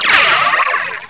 Laser2
LASER2.WAV